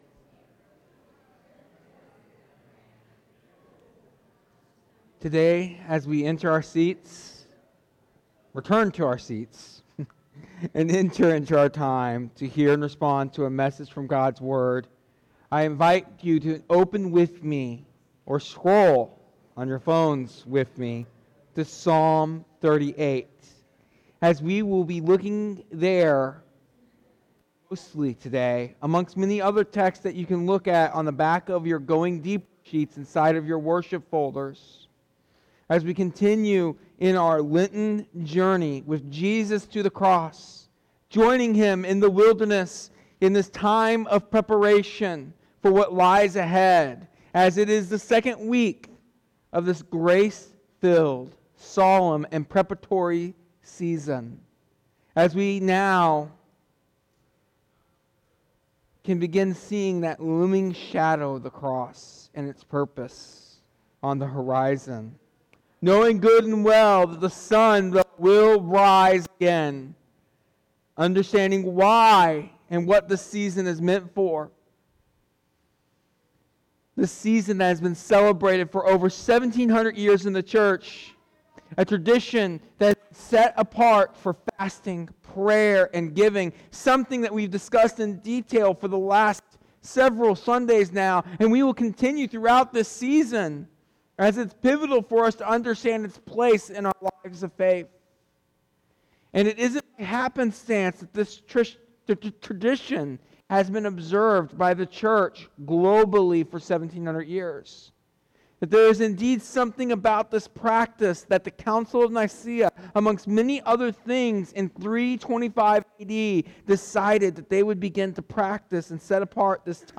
This sermon continues our Lenten journey through the Penitential Psalms, particularly Psalm 38, highlighting the weight and consequences of sin and the grace God provides in response.